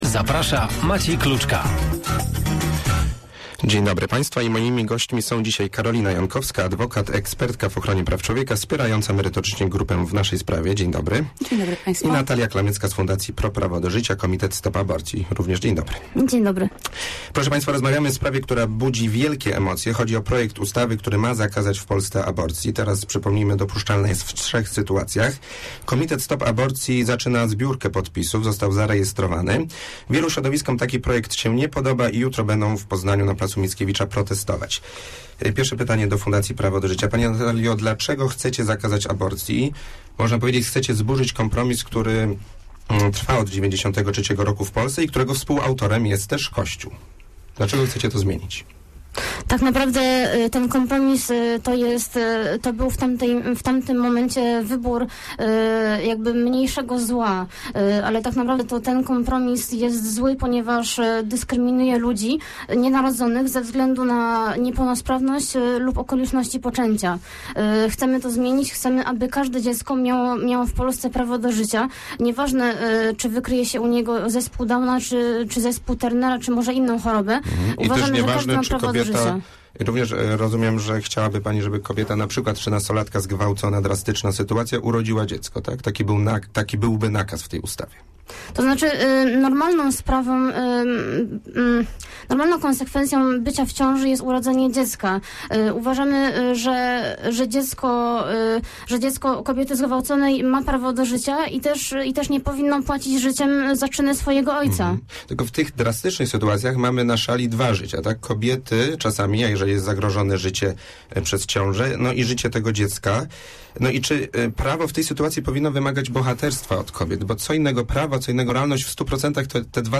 Obie strony sporu spotkały się dziś w Radiu Merkury.